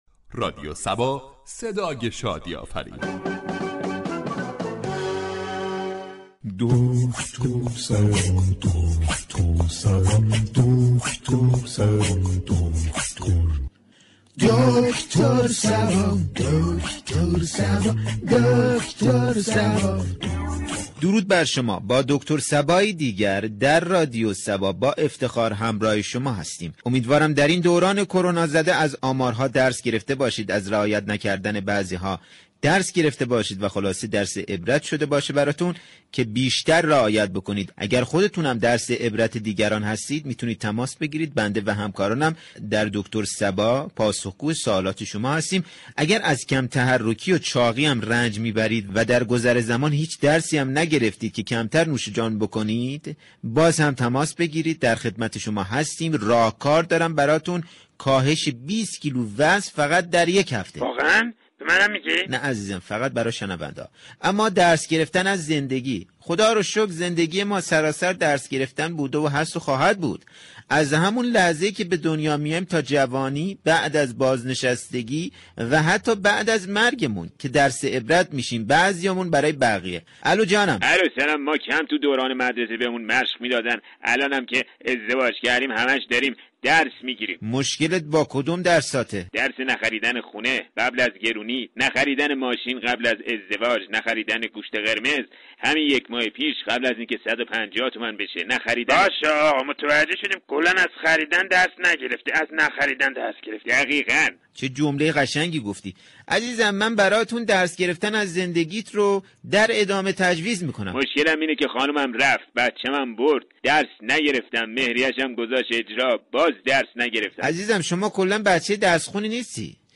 برنامه طنز "دكتر صبا " با بیان مسایل اجتماعی و فرهنگی با نگاهی طنز برای مخاطبان نسخه شادی و لبخند می پیچید.